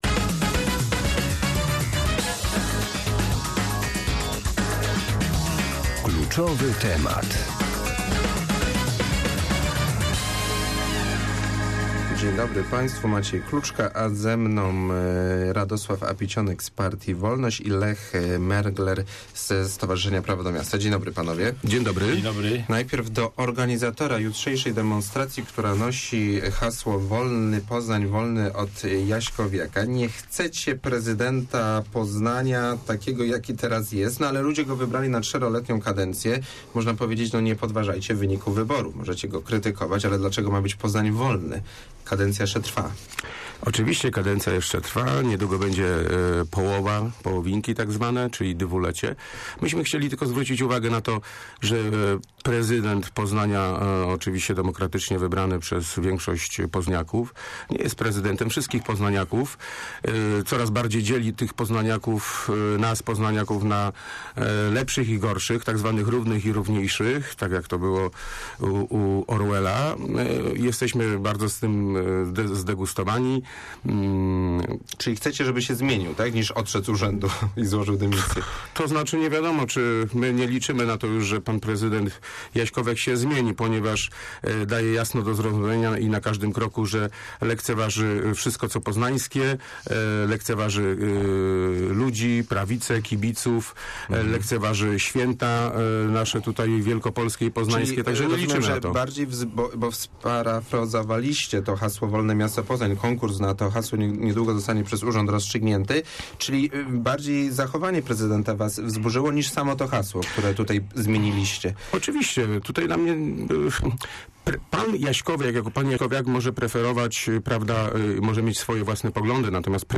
Dziś - w porannej rozmowie Radia Merkury - spotkali się ze zwolennikami prezydenta Jacka Jaśkowiaka.